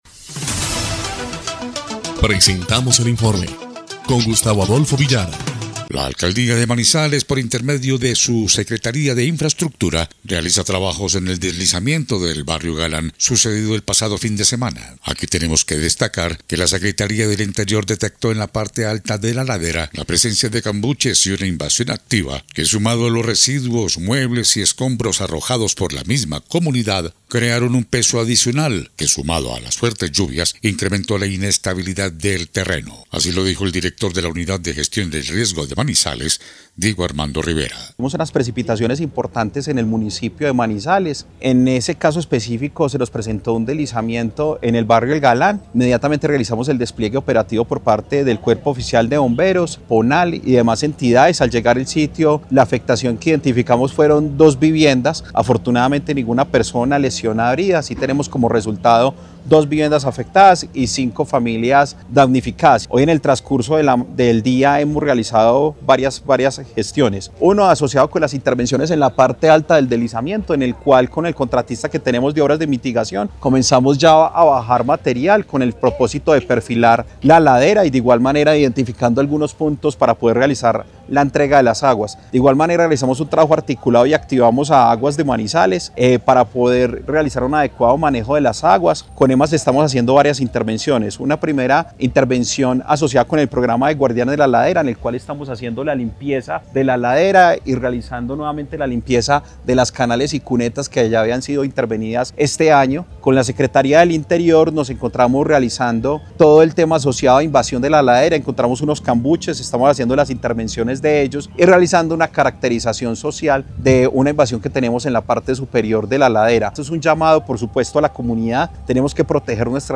EL INFORME 3° Clip de Noticias del 19 de noviembre de 2025